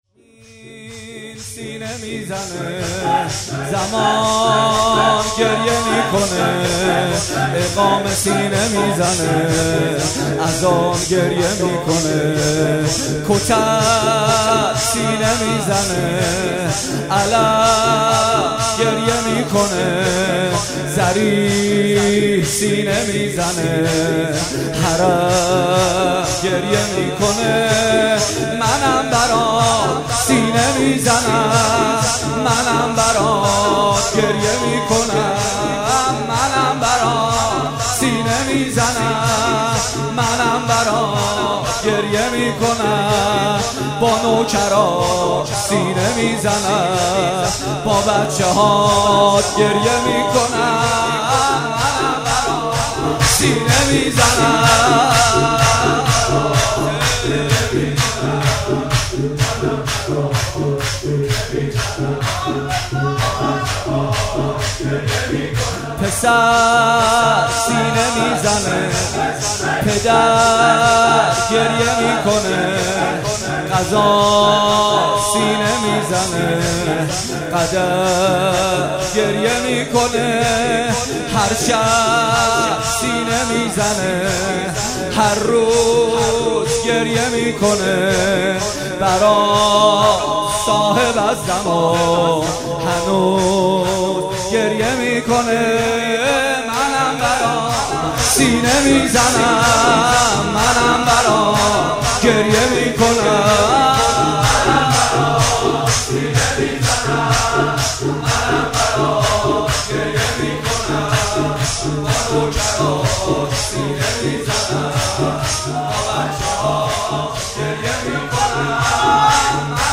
فاطمیه
شور محمد حسین پویانفر